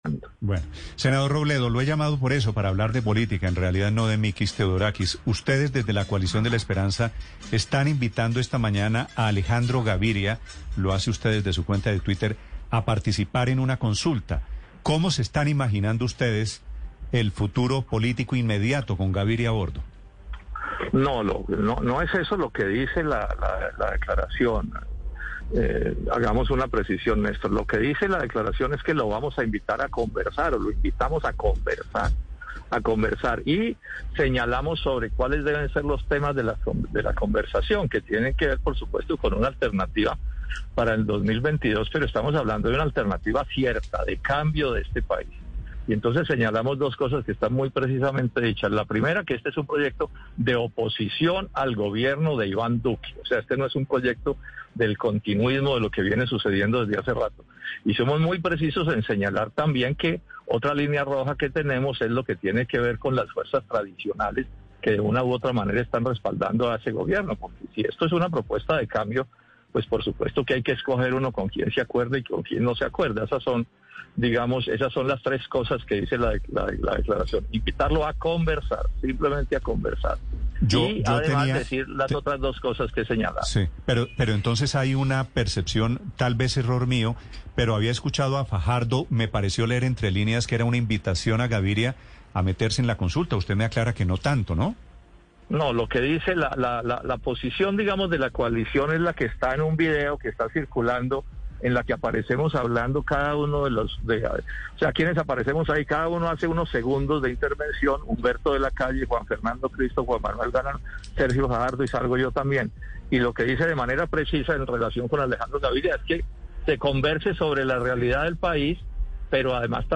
En entrevista con Mañanas BLU, 2 de septiembre de 2021